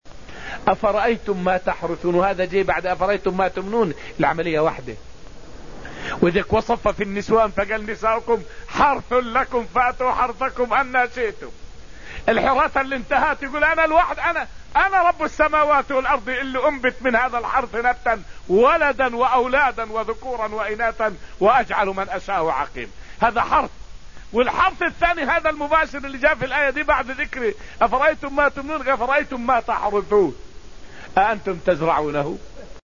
فائدة من الدرس السابع من دروس تفسير سورة الواقعة والتي ألقيت في المسجد النبوي الشريف حول لطيفة قرآنية.. مناسبة: {أفرأيتم ما تحرثون}، لما قبلها: {ما تمنون}.